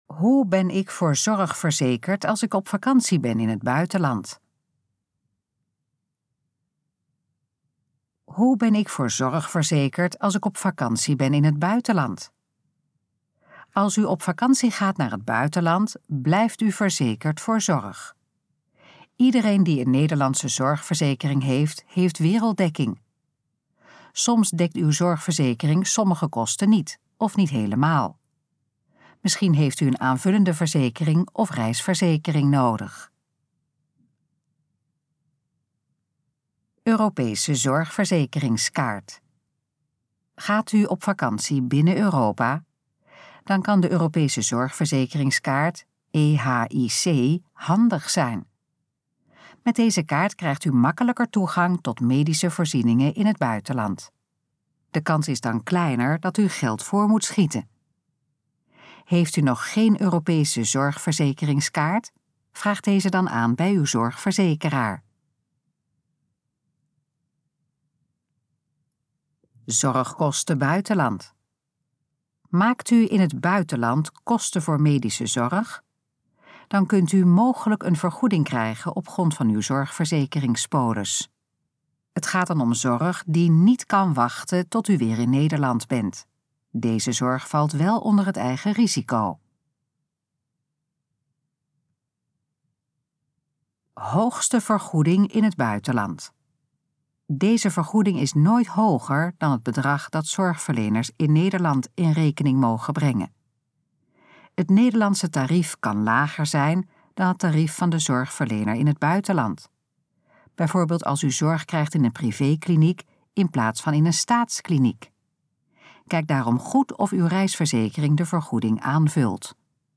Dit geluidsfragment is de gesproken versie van de pagina: Ben ik voor zorg verzekerd als ik op vakantie ben in het buitenland?